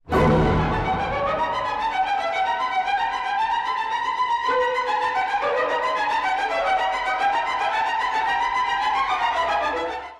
中間部は唐突に、吹雪のような混乱の情景で始まります。
まるで竜巻の中を、悪魔たちが浮かれて踊るよう。